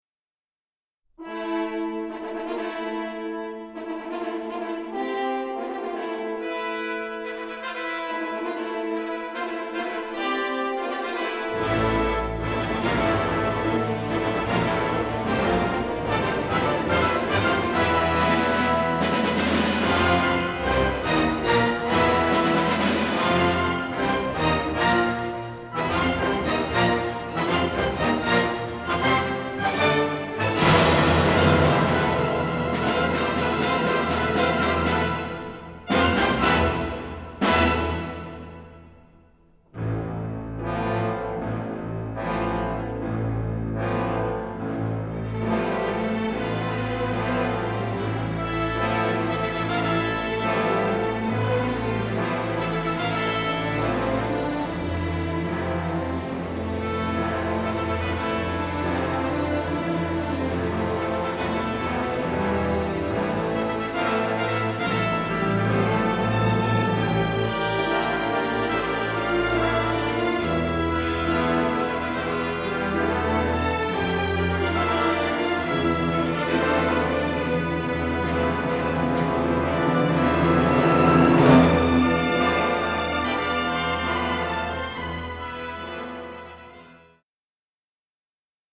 a rich, somber minor key theme for strings
It’s a dark-tinged and brooding line that portends tragedy.
soprano
records and mixes at AIR Studios in London